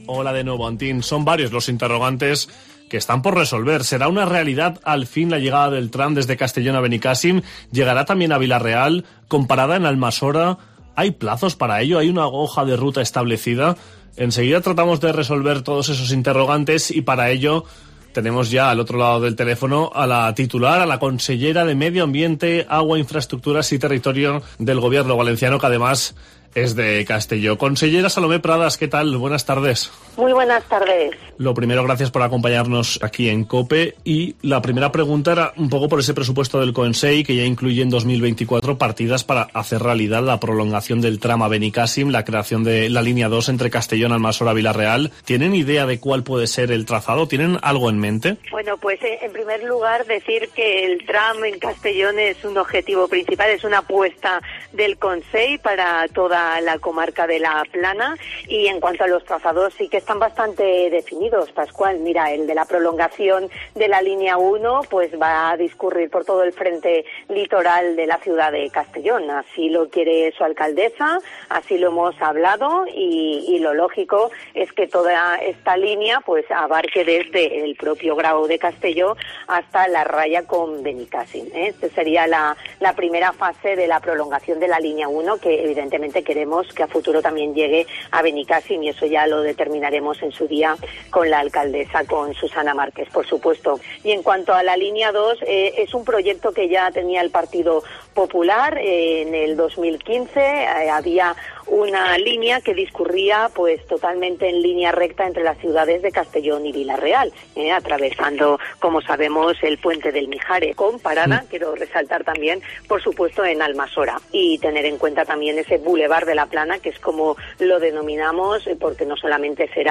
La consellera de Medio Ambiente, Agua, Infraestructuras y Territorio, Salomé Pradas, se pasa por los micrófonos de COPE tras presentar las cuentas que formarán parte de los presupuestos de la Generalitat de cara al 2024.